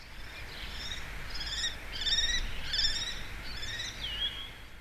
Lagarteiro común
Canto